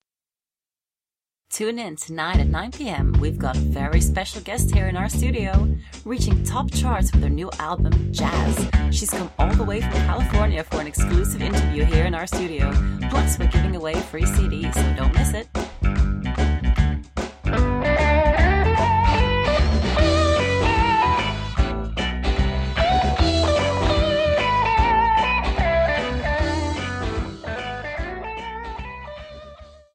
Native English speaker.
Experienced voice-over artist with professional studio and sound editing skills. Clear, neutral (international) accent. Voice is warm, authentic, reassuring and has a very personal sound. British and American accents.
Sprechprobe: Sonstiges (Muttersprache):